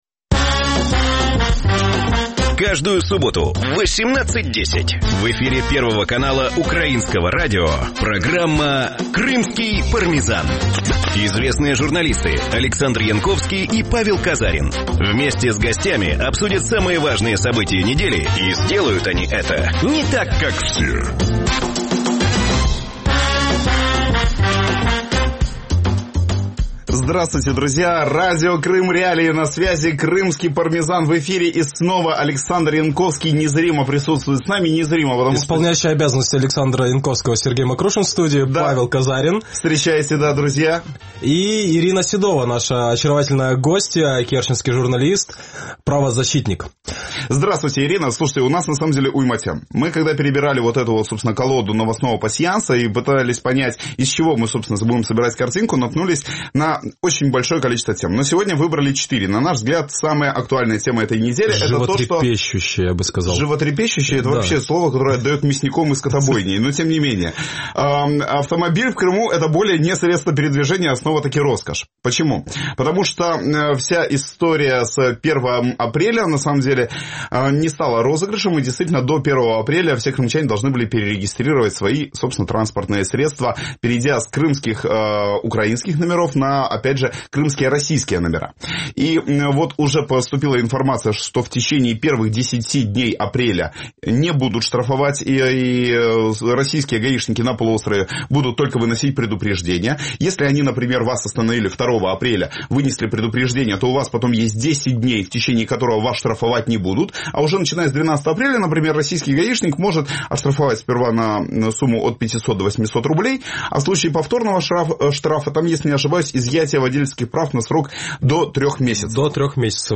Программа звучит в эфире Радио Крым.Реалии. Это новый, особенный формат радио.